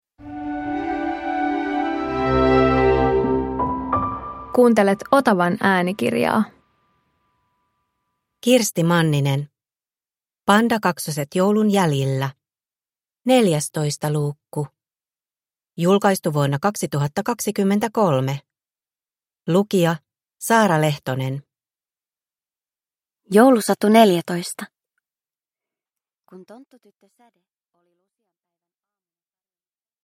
Pandakaksoset joulun jäljillä 14 – Ljudbok